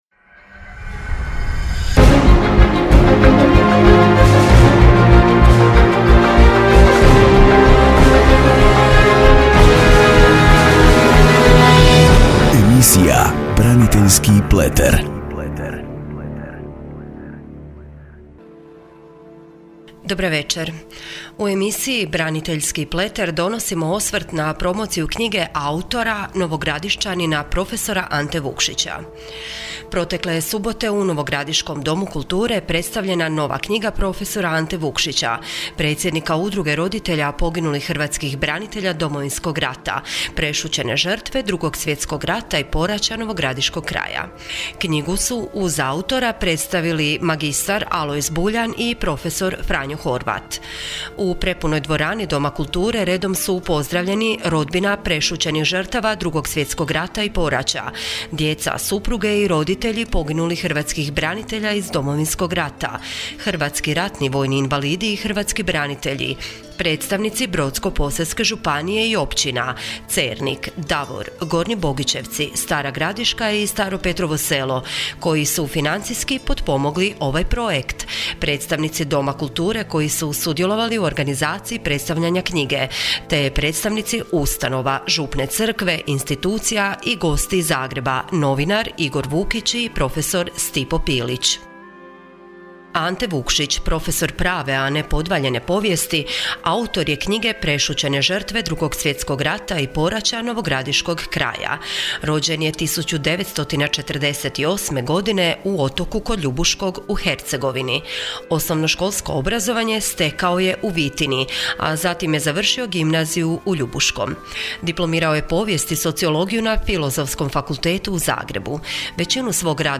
Radio Nova Gradiška (98.1Mhz) — Radijska emisija: “BRANITELJSKI PLETER”